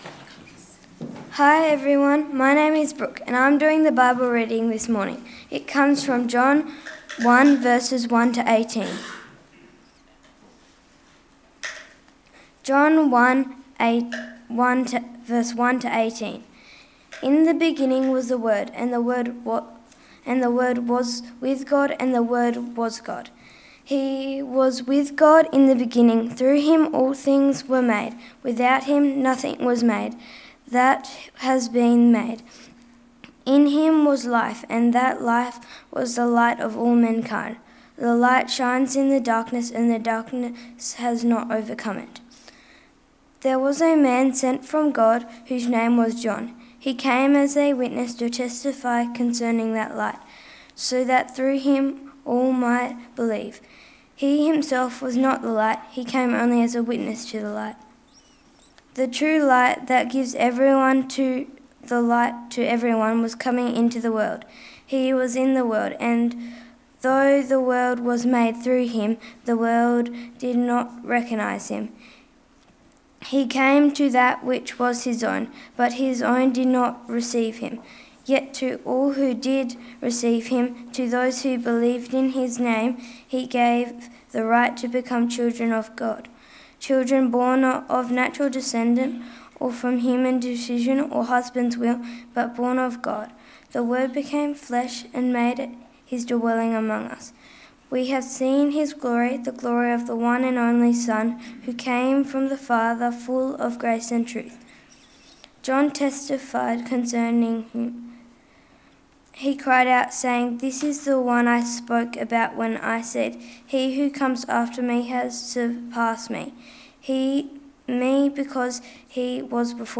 Text: John 1: 1-18 Sermon